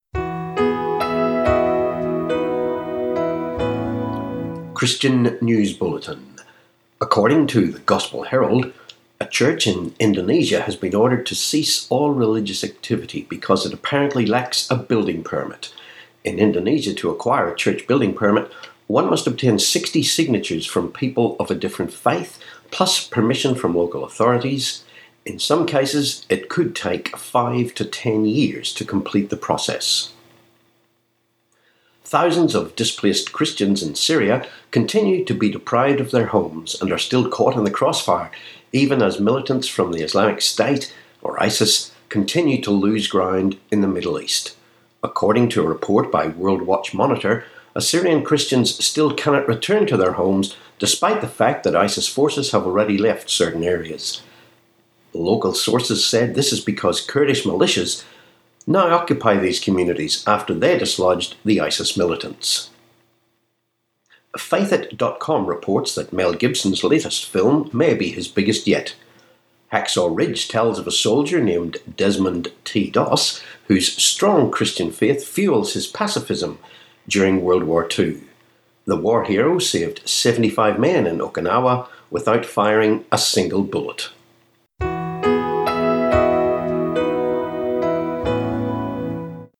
16Oct16 Christian News Bulletin